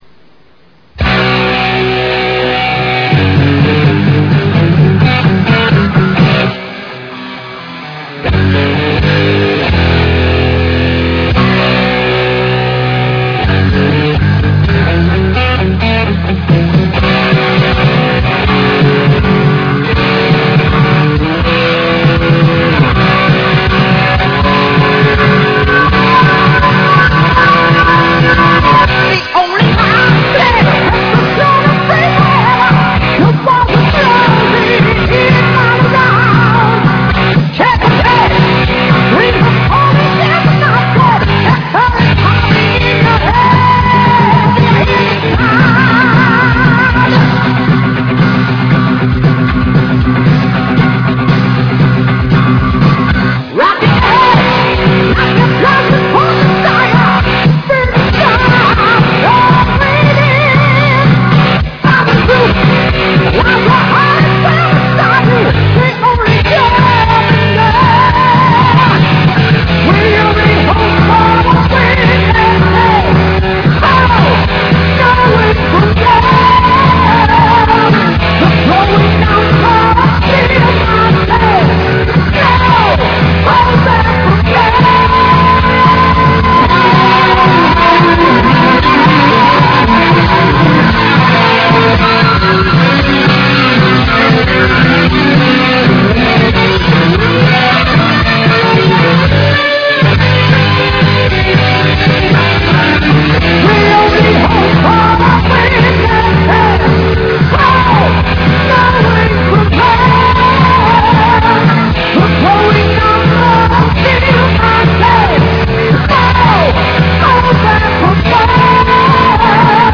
Unveröffentlichter Demo-Song.